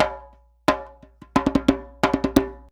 089DJEMB16.wav